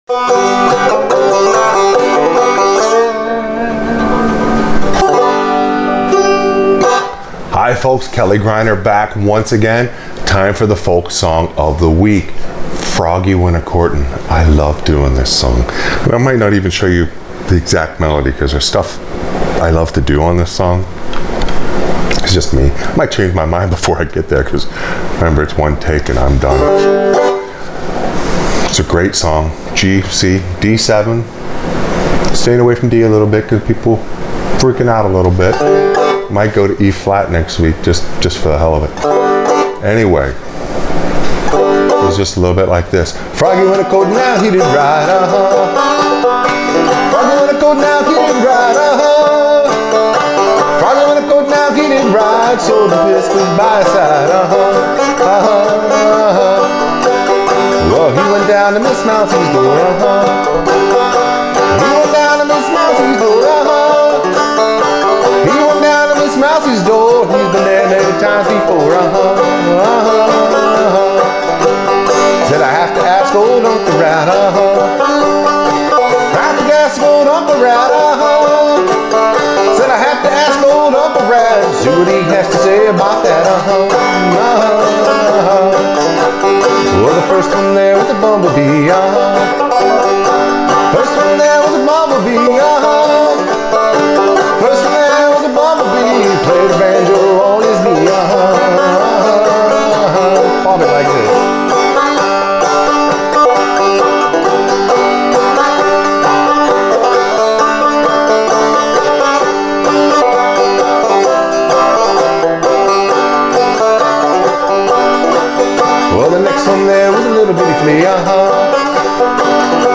Clawhammer BanjoFolk Song Of The WeekInstruction
I love singing and playing this song.